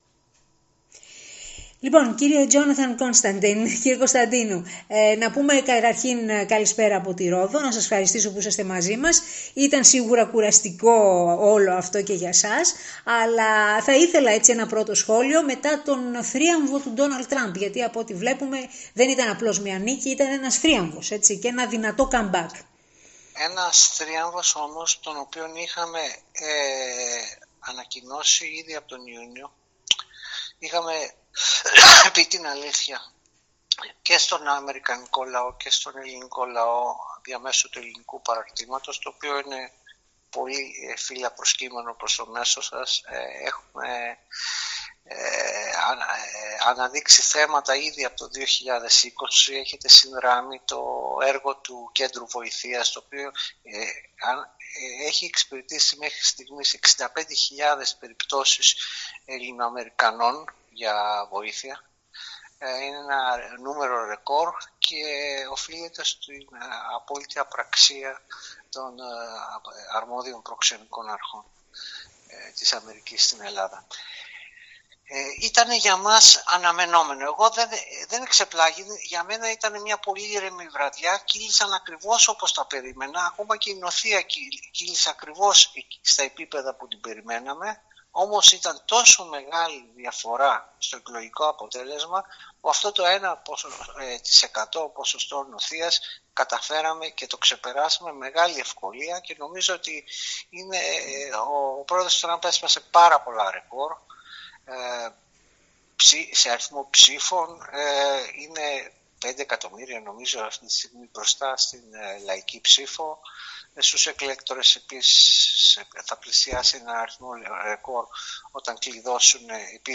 ραδιοφωνική συνέντευξή